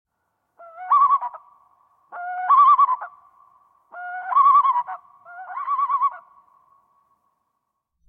Loon Call Téléchargement d'Effet Sonore
Loon Call Bouton sonore